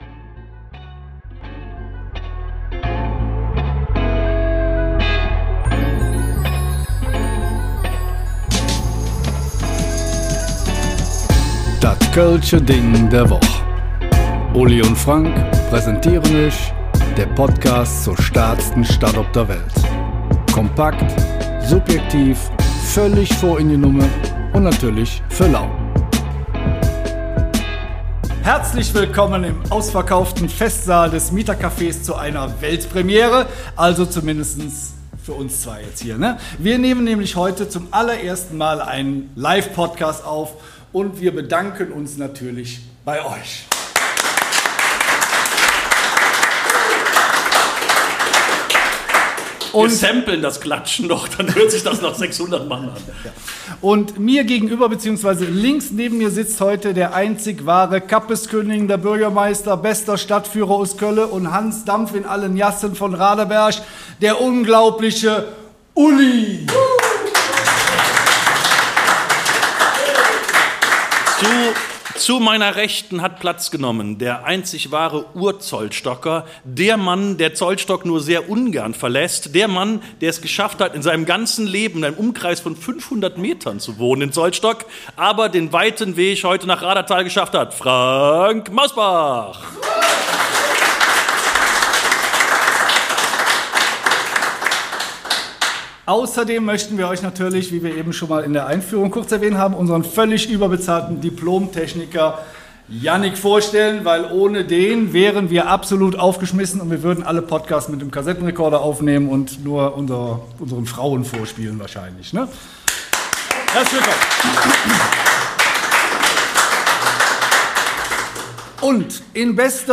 Beschreibung vor 1 Jahr Raderthal – Fritz-Encke-Park, Englische Siedlung und mehr (live vor Publikum) Im Mai haben wir es gewagt, zwei Folgen unseres Podcasts live vor Publikum aufzunehmen.